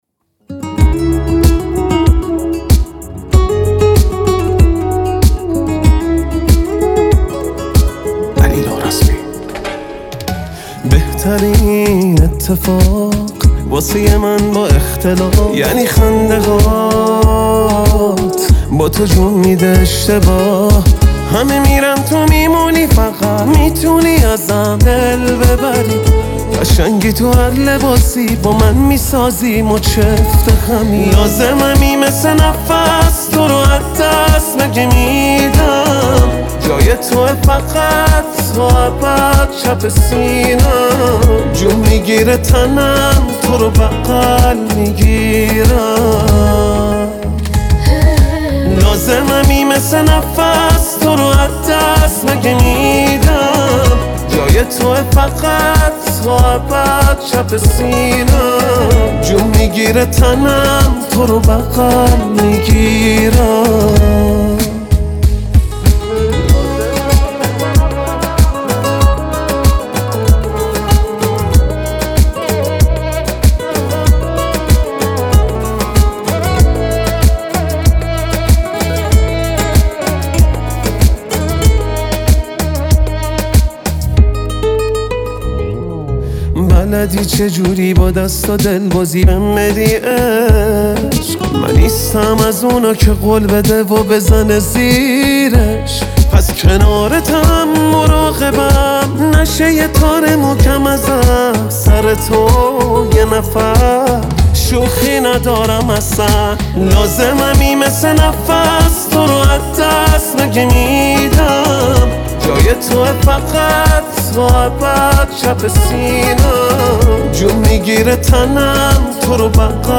اهنگ رمانتیک
اهنگ عاشقانه